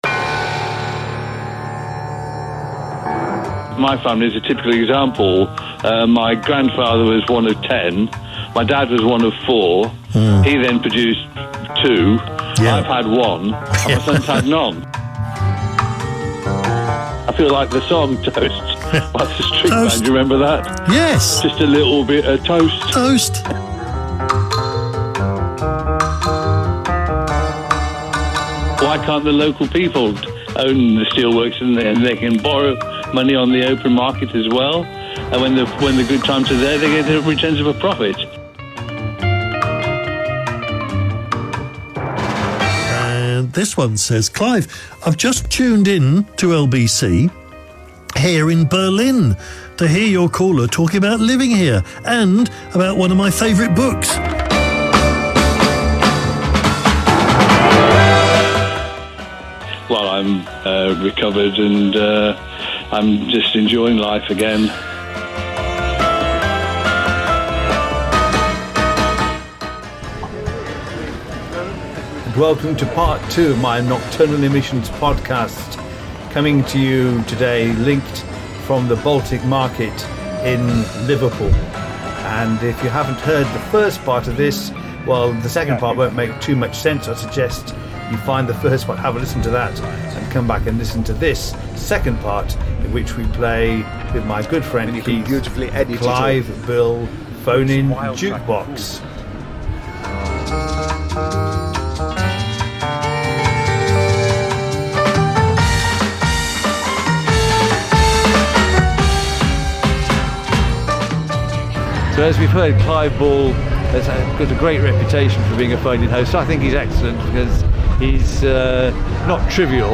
A diverse range of topics and moods for sure! Pictured is the portable recorder I used for the links in Liverpool.